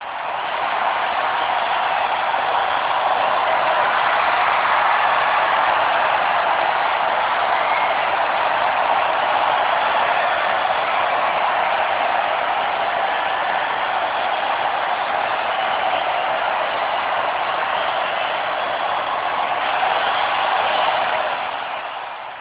Crowd.amr